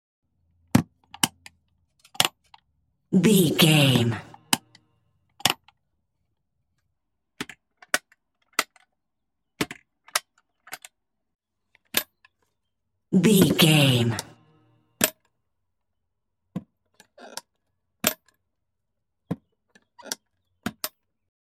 Ambulance open close small doors
Sound Effects